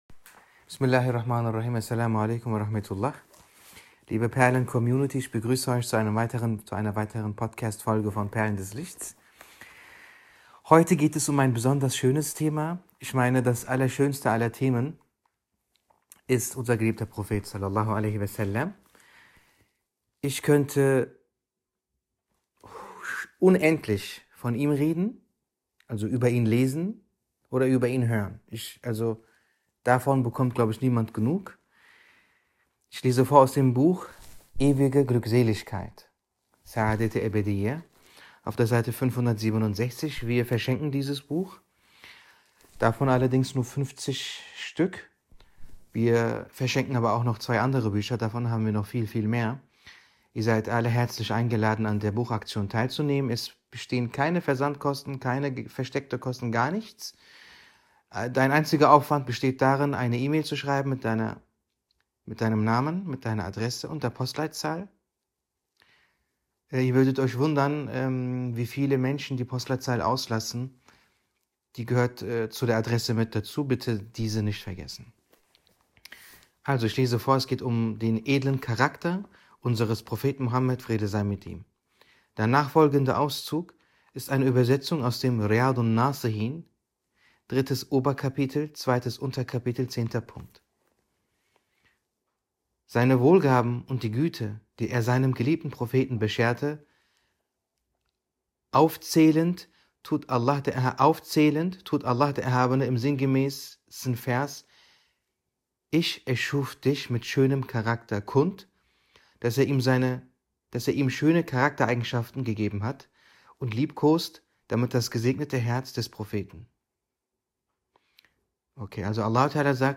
Stil & Sprache: Sanft, poetisch und tief.